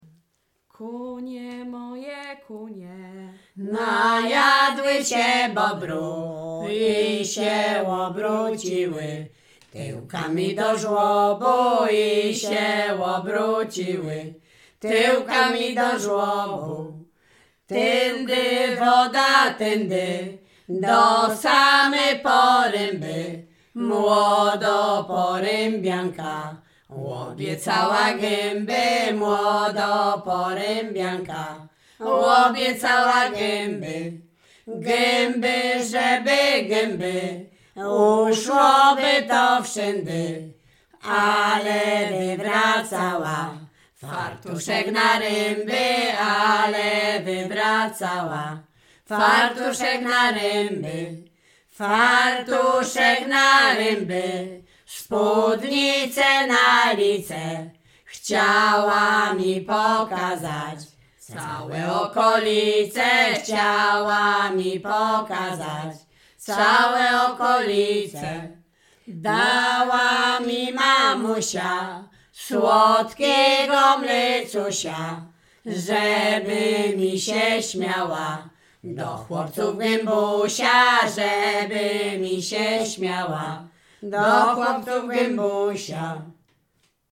Śpiewaczki z Mroczek Małych
województwo łodzkie, powiat sieradzki, gmina Błaszki, wieś Mroczki Małe
Przyśpiewki
miłosne żartobliwe przyśpiewki